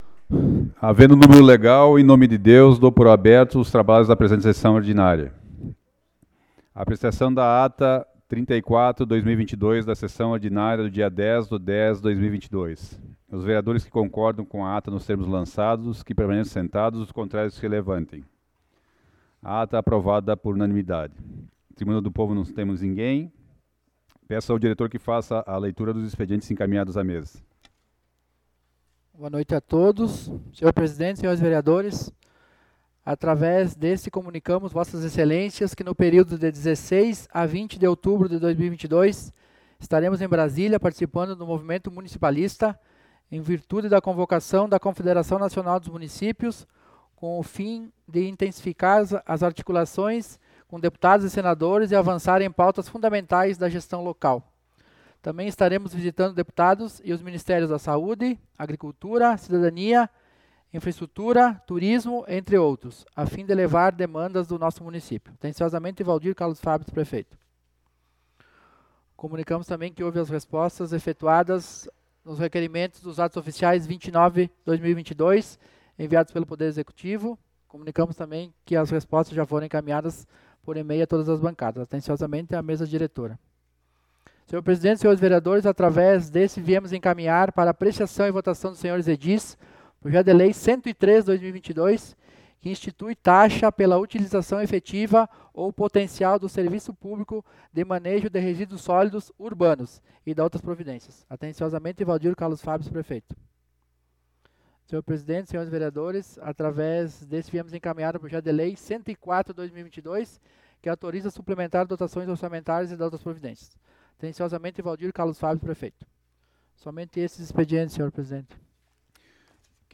Sessão Ordinária do dia 17 de Outubro de 2022 - Sessão 35